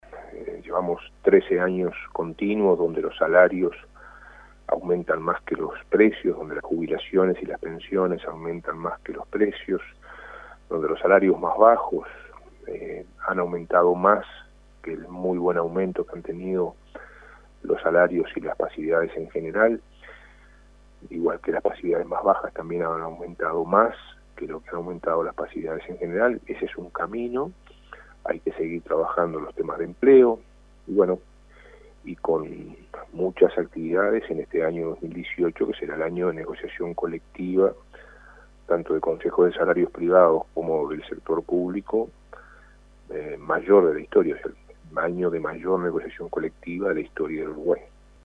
Este sábado el ministro de Trabajo y Seguridad Social, Ernesto Murro, dialogó con Informativo 810 y aseguró que "se llevan 13 años donde los salarios aumentan más que los precios".